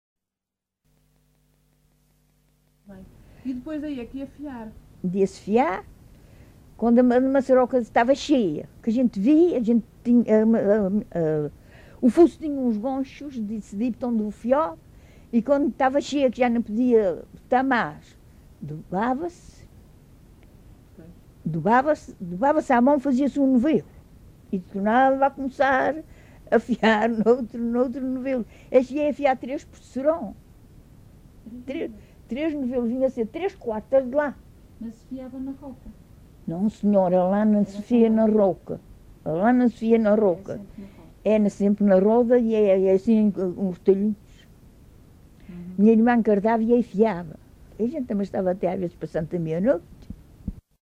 LocalidadeCosta do Lajedo (Lajes das Flores, Horta)